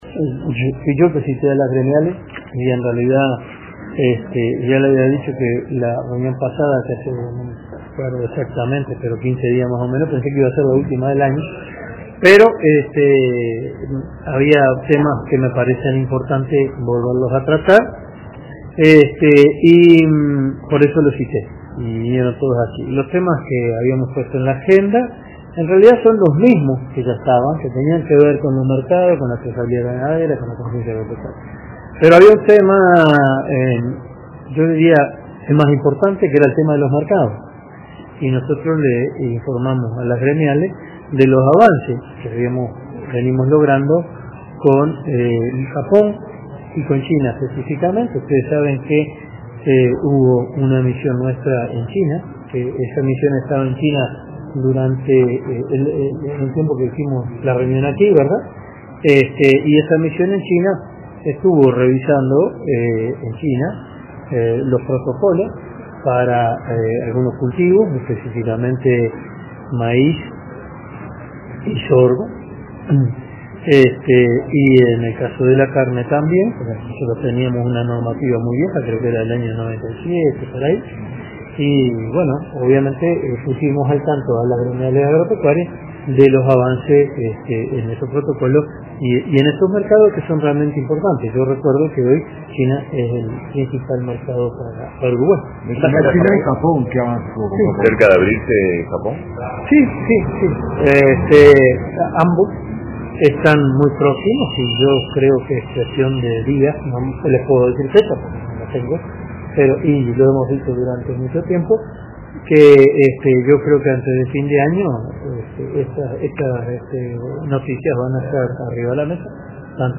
Antes de finalizar el año, Uruguay concretará la apertura del mercado de Japón para la venta de carne y actualizará protocolos de carne y de cultivo de maíz y sorgo con China, afirmó el ministro de Ganadería, Enzo Benech, tras la reunión con las gremiales agropecuarias este martes. Otro tema abordado fue la trazabilidad ganadera y conciencia agropecuaria.